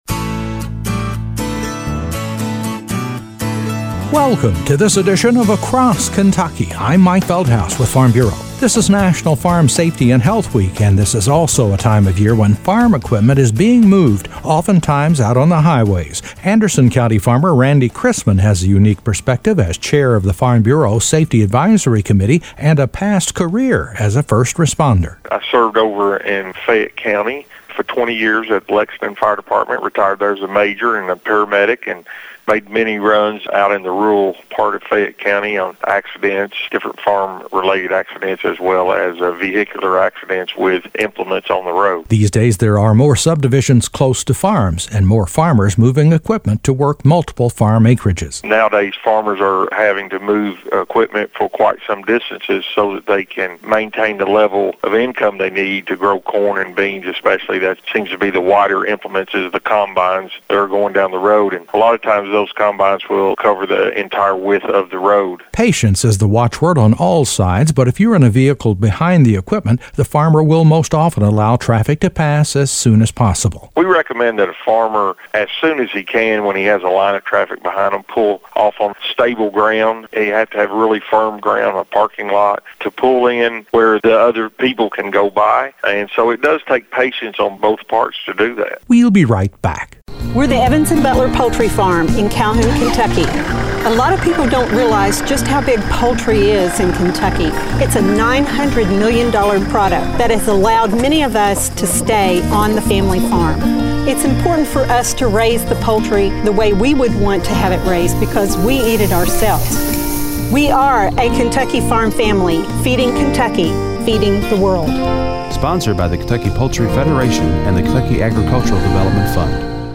A report on the seasonal dangers of farm equipment being moved on Kentucky’s highways.